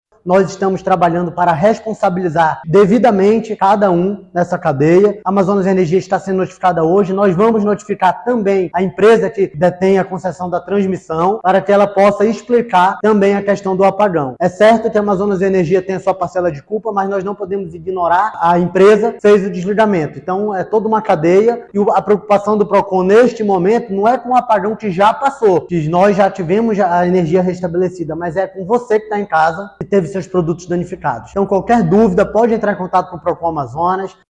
SONORA02_JALIL-FRAXE.mp3